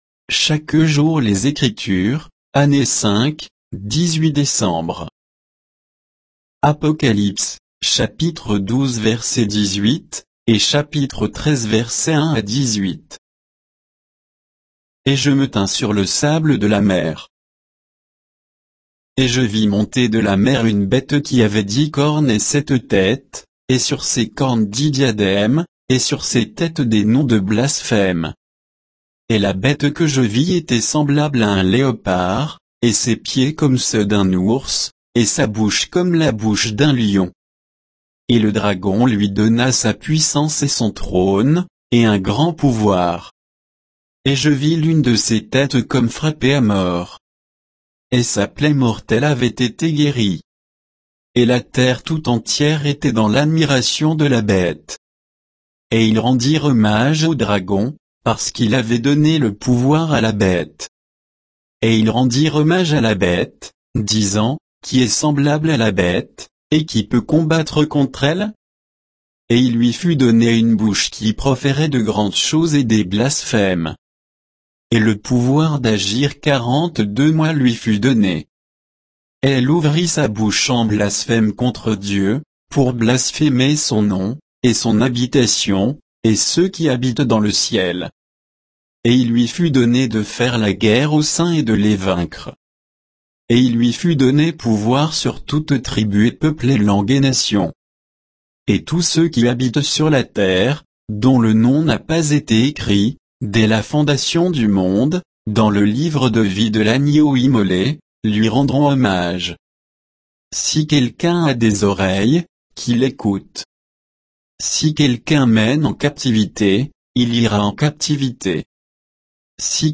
Méditation quoditienne de Chaque jour les Écritures sur Apocalypse 12, 18 à 13, 18